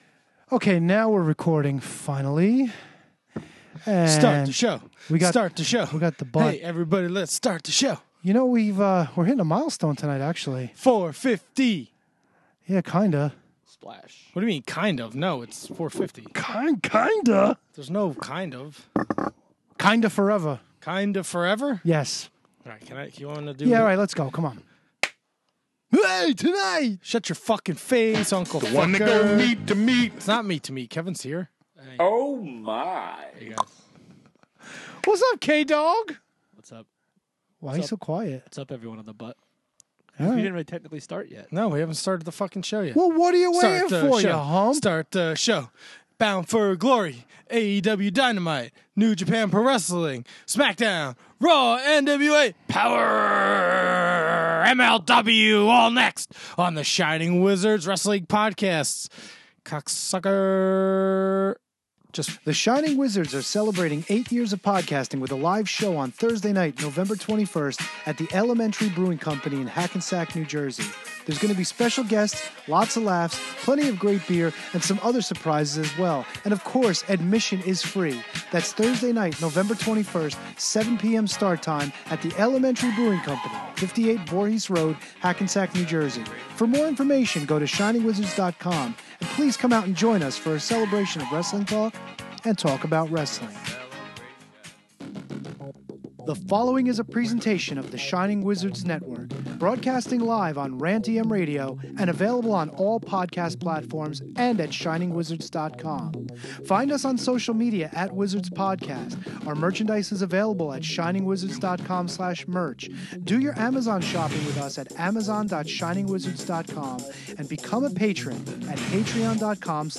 It’s another rainy night in Studio A, and the boys open with some disappointments.